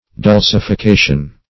Search Result for " dulcification" : The Collaborative International Dictionary of English v.0.48: Dulcification \Dul`ci*fi*ca"tion\, n. [Cf. F. dulcification.] The act of dulcifying or sweetening.
dulcification.mp3